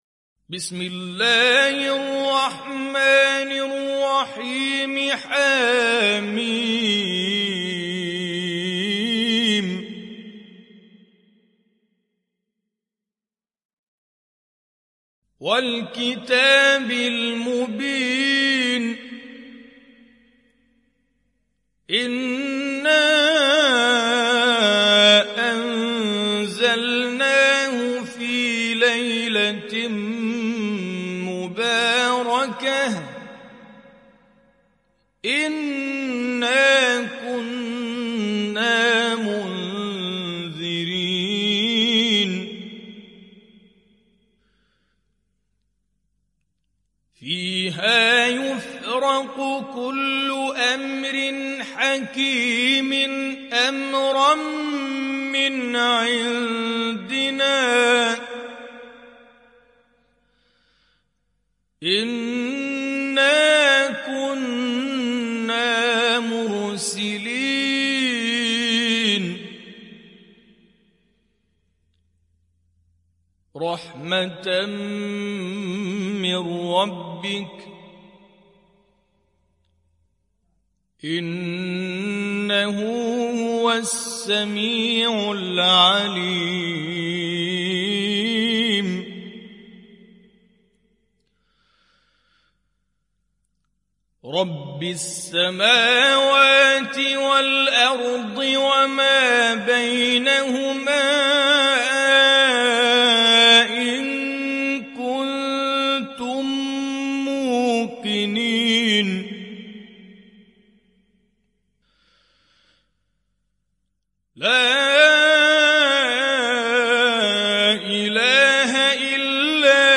Hafs থেকে Asim